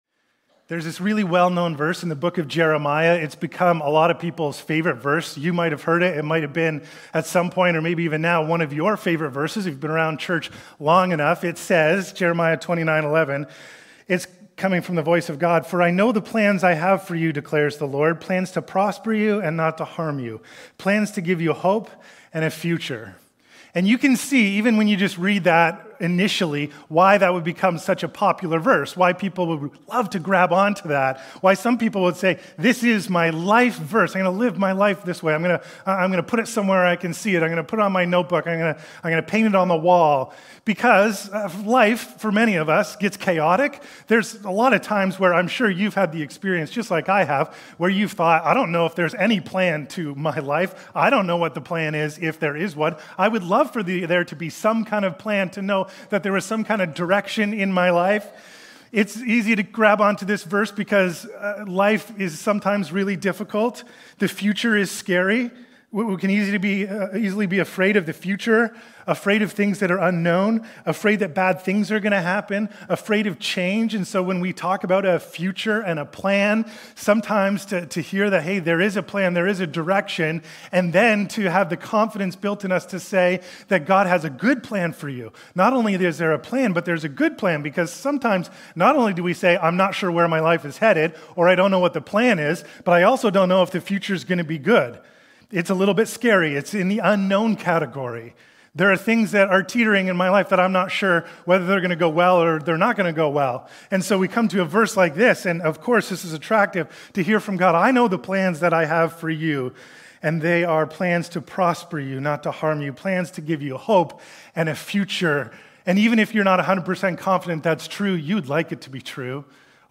Sermons | Westside Church